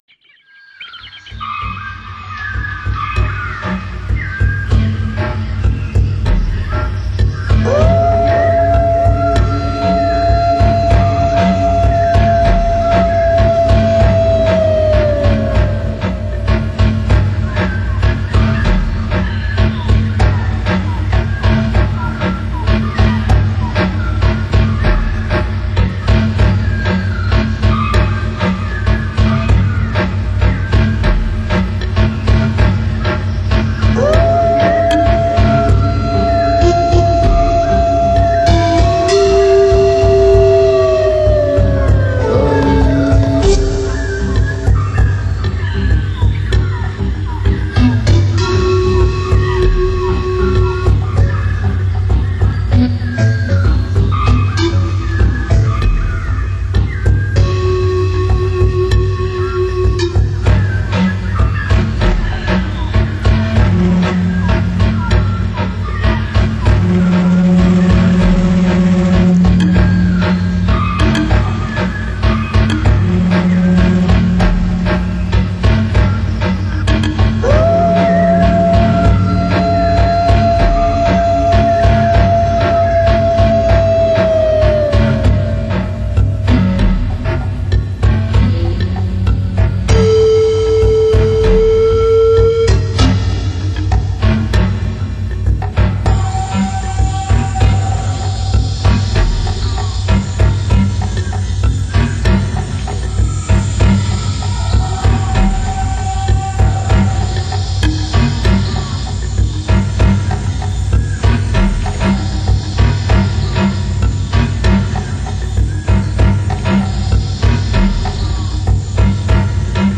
音乐类别 ： 新世纪音乐
专辑特色 ： 山川大地的脉动
透过变幻多端的电子合成，营造了缤纷多彩的效果。
为了使音乐更加通俗易懂，唱片的制作中加入了大量实地采样的真实效果，像真度之高堪称离奇，犹如置身深山老林，身临其境。
此外，音乐中无处不在的野性节奏，亦是对阁下器材最残忍的挑战。